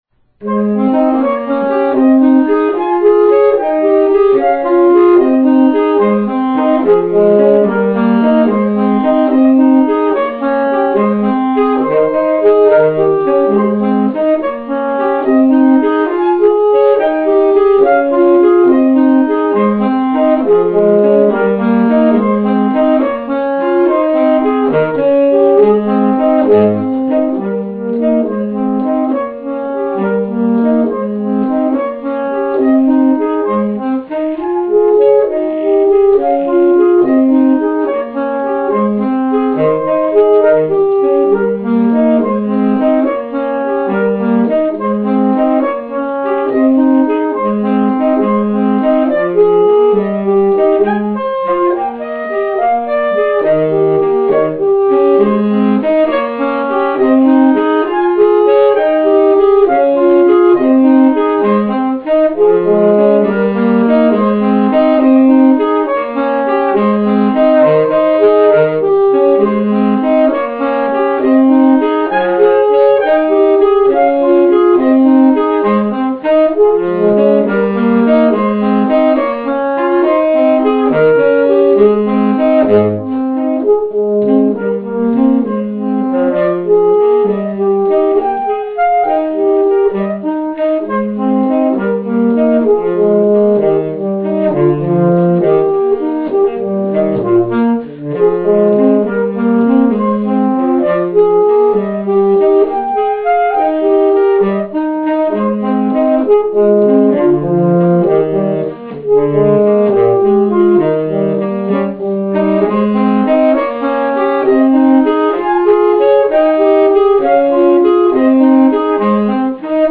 for two saxophones (tenor and baritone) of this ballade.